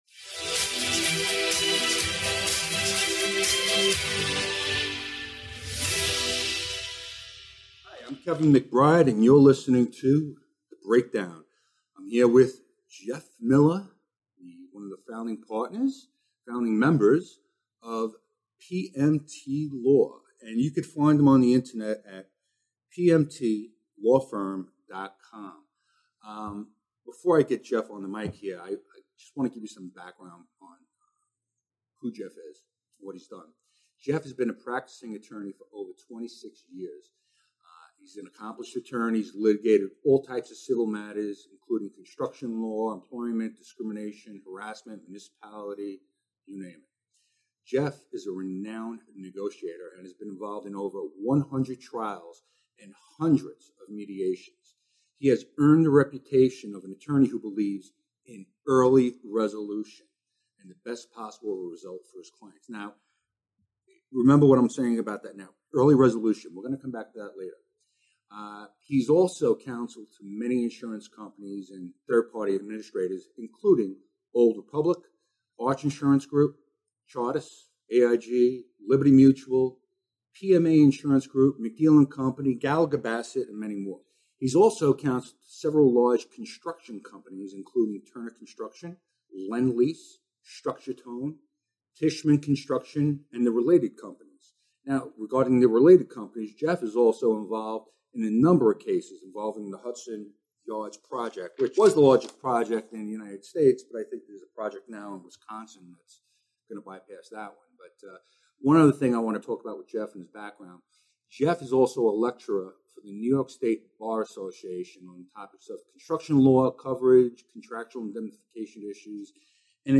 The Breakdown is an informative and equally entertaining podcast discussion that addresses the insurance issues faced by NY contractors and developers today. Experts across multiple fields sharing their “insurance playbook” in a round table podcast format.